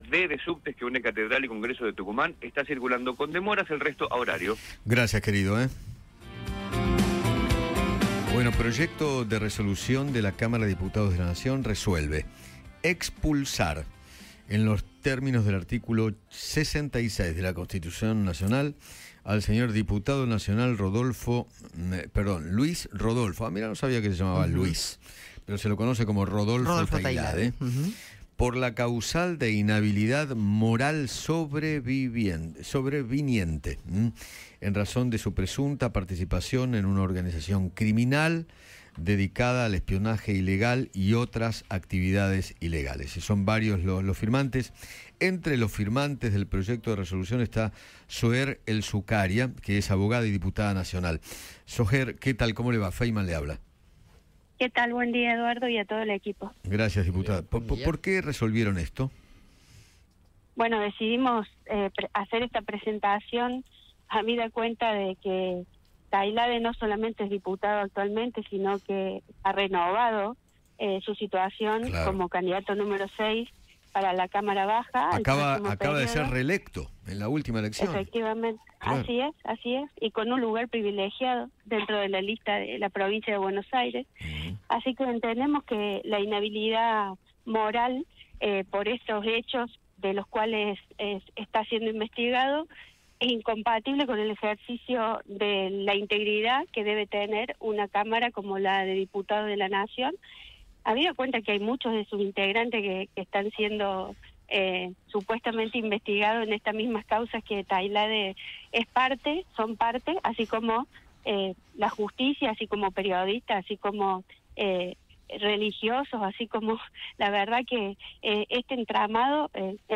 Soher El Sukaria, abogada y diputada nacional, dialogó con Eduardo Feinmann sobre el pedido de desafuero del oficialista Rodolfo Tailhade, tras el escándalo del espionaje ilegal.